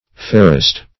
ferrest - definition of ferrest - synonyms, pronunciation, spelling from Free Dictionary Search Result for " ferrest" : The Collaborative International Dictionary of English v.0.48: Ferrest \Fer"rest\, a. & adv.